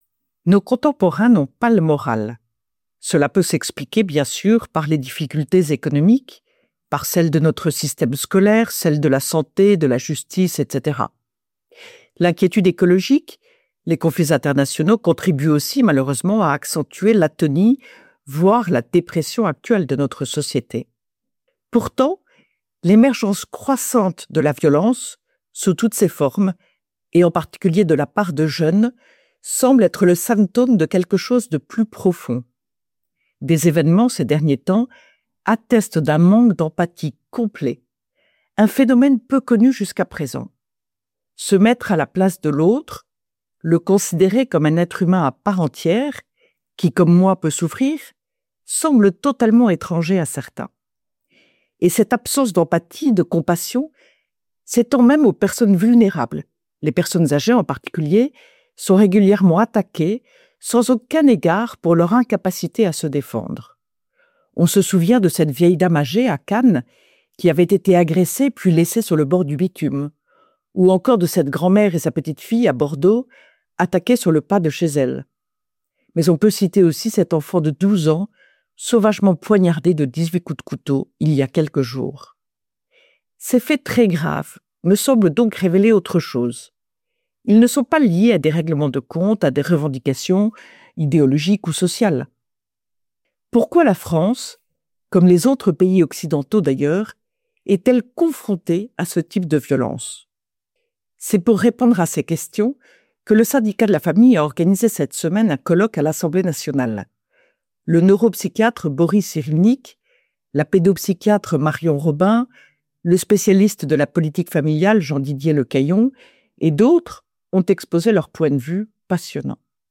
« Esprit de Famille » : Retrouvez chaque semaine la chronique de Ludovine de La Rochère, diffusée le samedi sur Radio Espérance, pour connaître et comprendre, en 3 minutes, l’essentiel de l’actualité qui concerne la famille.